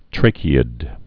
(trākē-ĭd, -kēd)